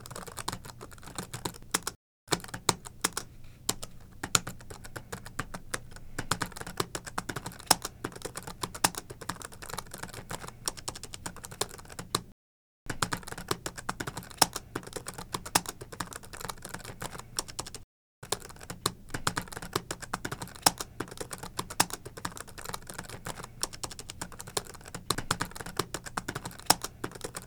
computerkeyboardtyping
computer email fast keyboard typing sound effect free sound royalty free Memes